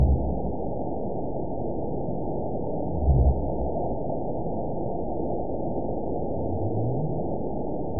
event 920769 date 04/08/24 time 04:30:35 GMT (1 year, 1 month ago) score 9.59 location TSS-AB02 detected by nrw target species NRW annotations +NRW Spectrogram: Frequency (kHz) vs. Time (s) audio not available .wav